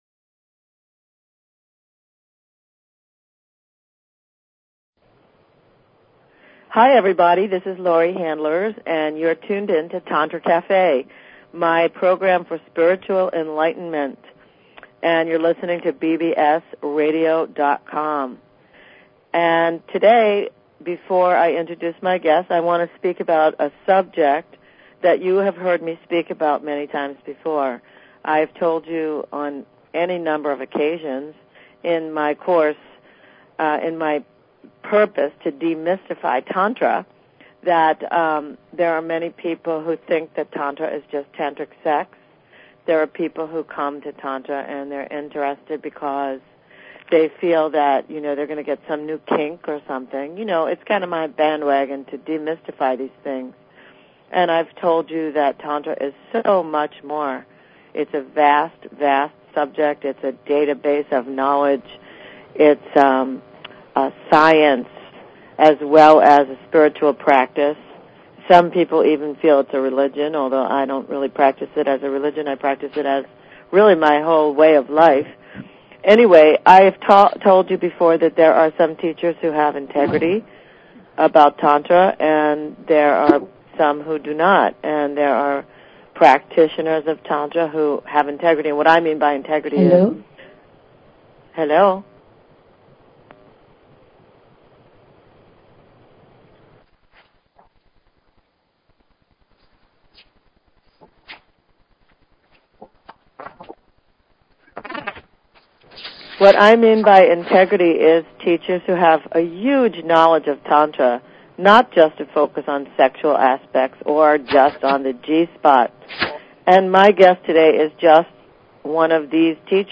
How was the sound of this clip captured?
LIVE call-in Karma Readings /moneysexpower#archives Health & Lifestyle Philosophy Psychology Self Help Spiritual 0 Following Login to follow this talk show Money Sex Power Whats Your Karma